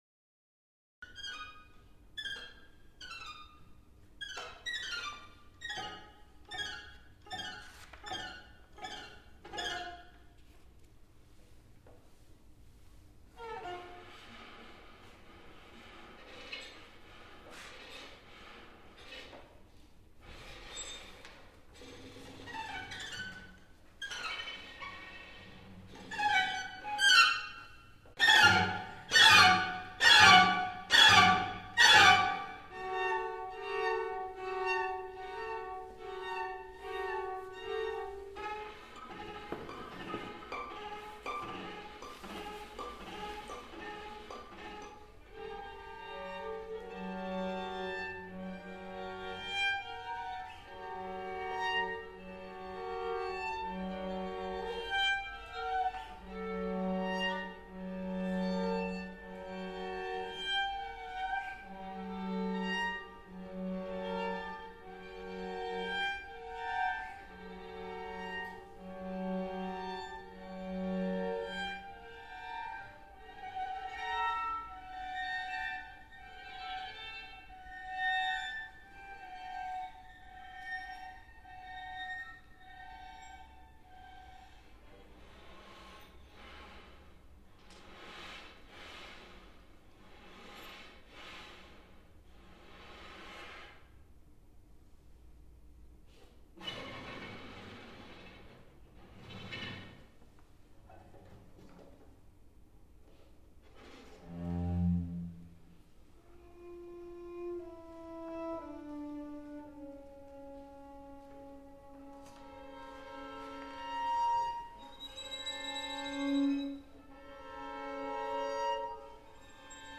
Description: String Quartet No.1, Erratic (Mov.I)